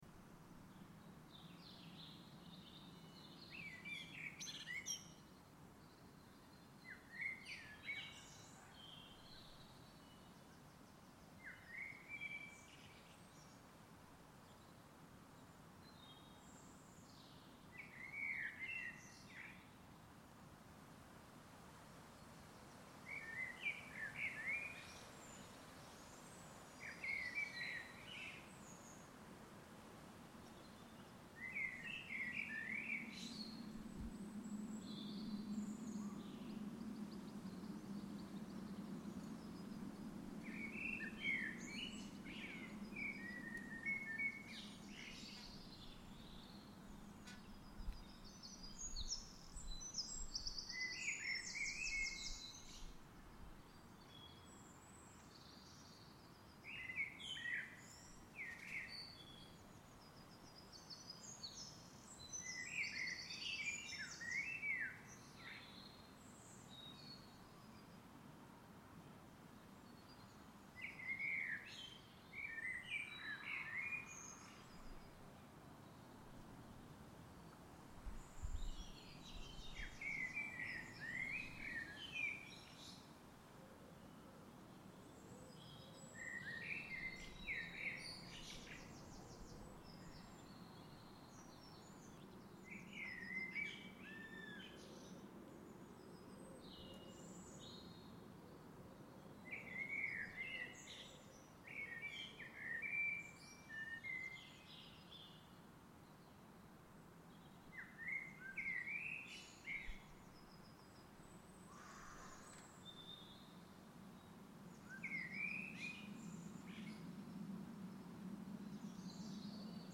Garden birdsong 11 April recorded with my new Zoom H1 Essential
A relaxing, stereo recording of Thursday evening birdsong in my peaceful garden.